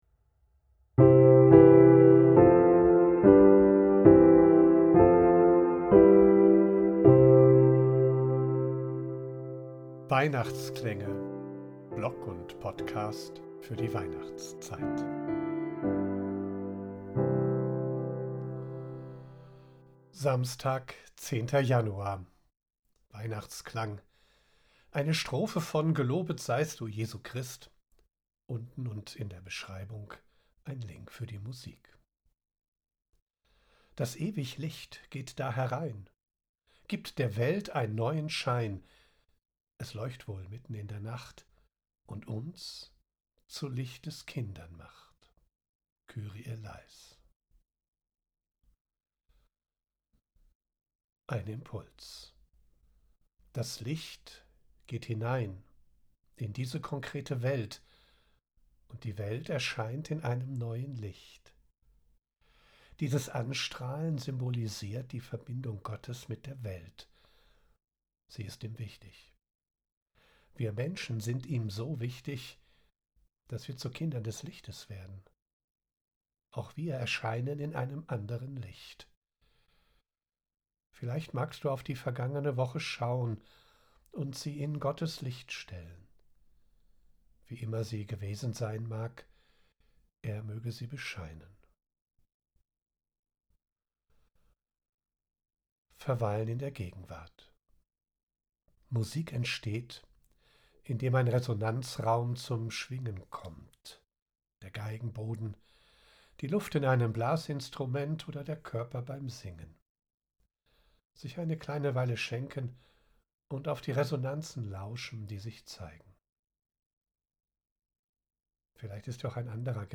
Gelobet seist du Jesu Christ, Bachchor Siegen: